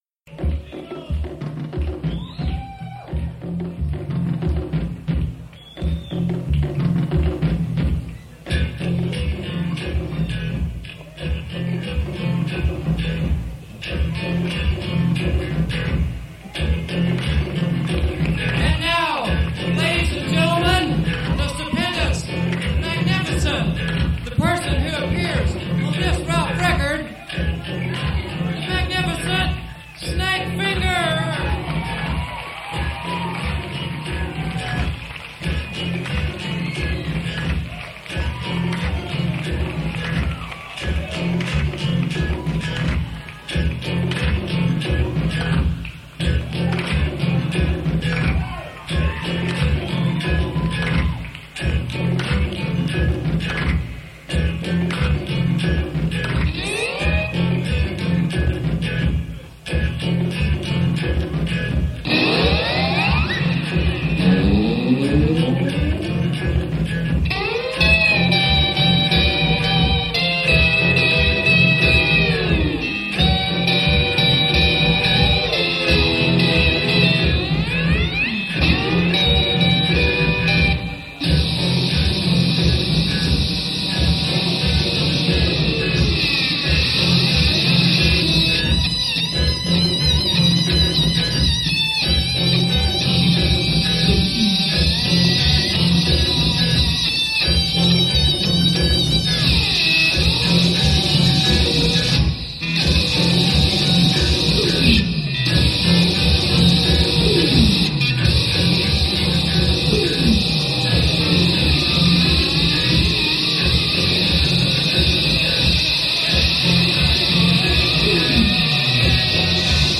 he was originally a British blues guitarist
From blues to Avant-garde.